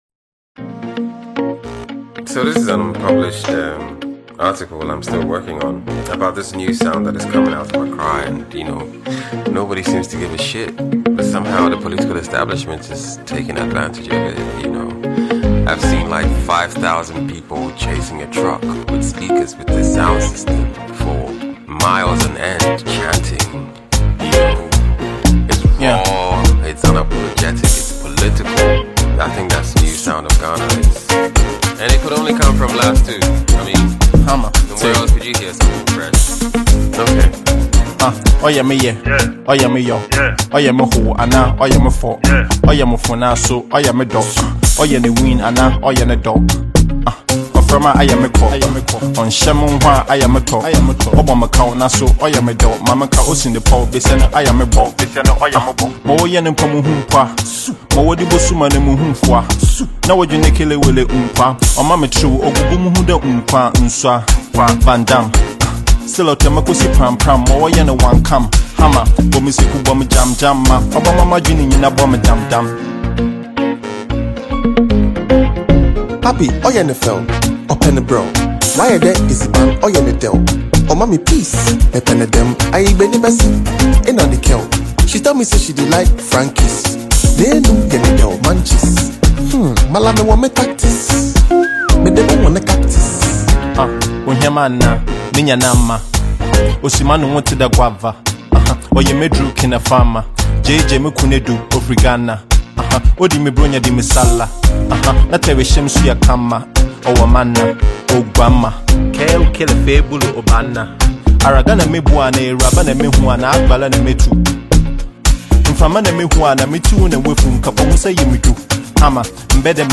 a renowned Ghanaian producer
Ghana Afrobeat MP3